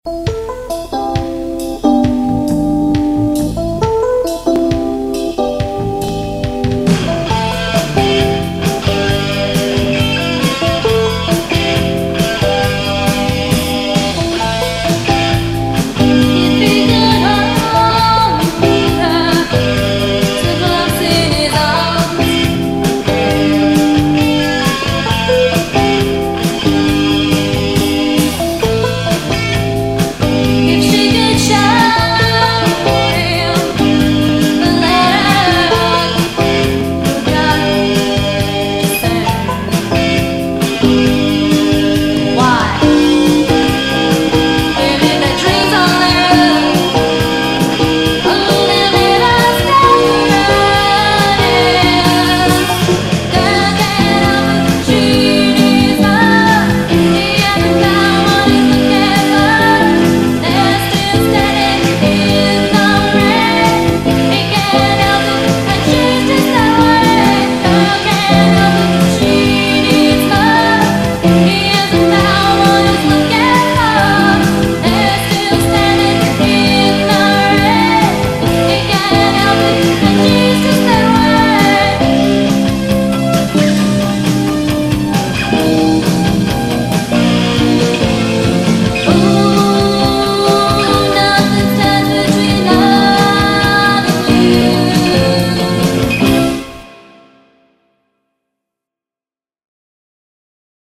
Recorded at Mid-South Audio in Georgetown DE